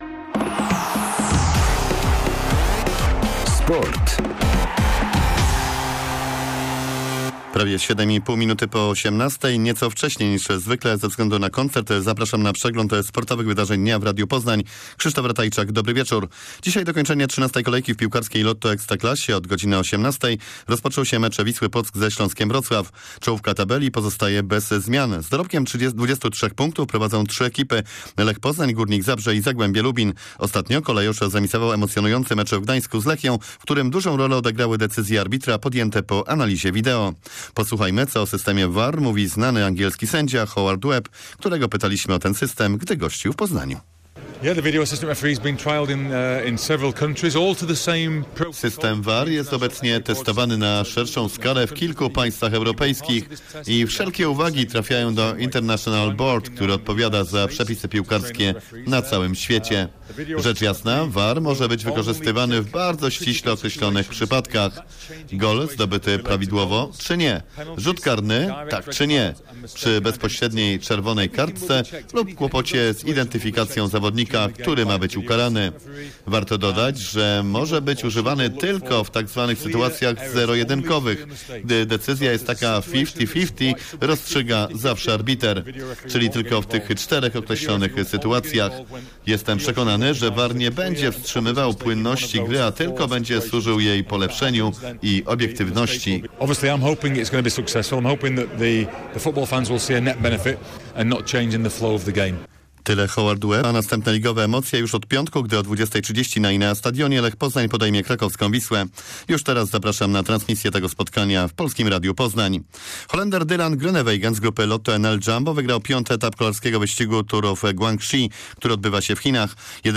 23.10 serwis sportowy godz. 18:05